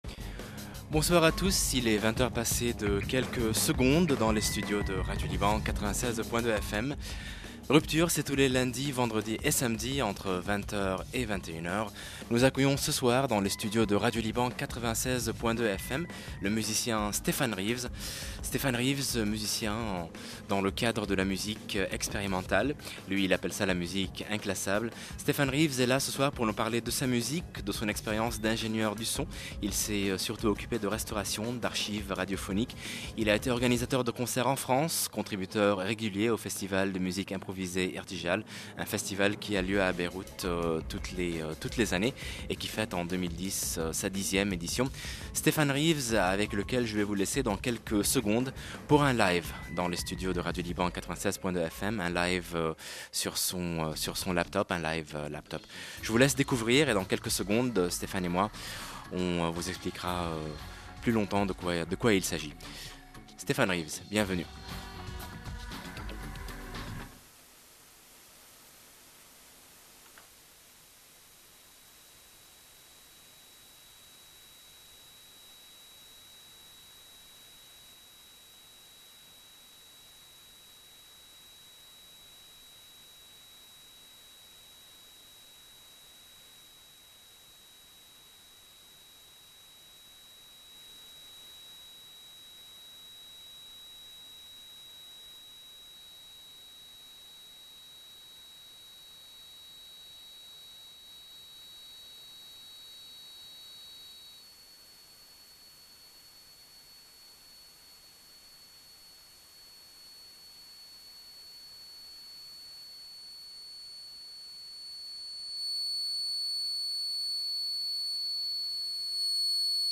An interview and live performance